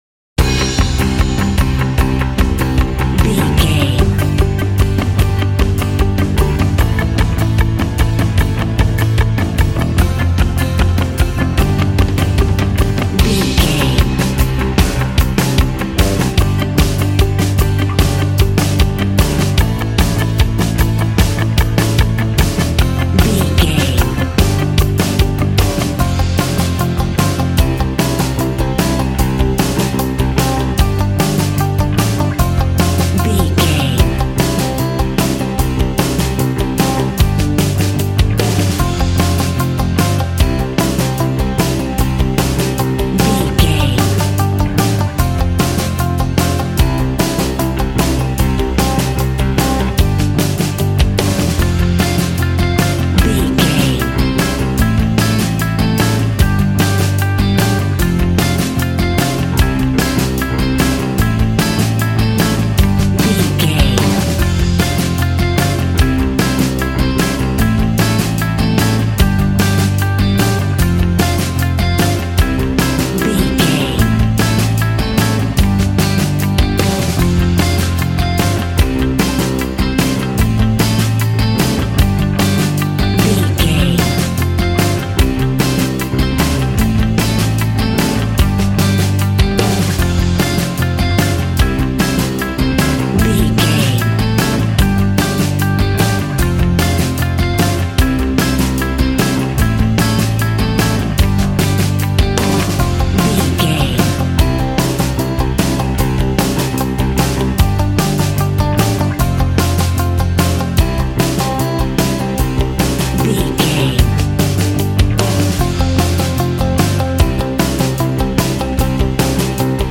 Ionian/Major
groovy
powerful
organ
drums
bass guitar
electric guitar
piano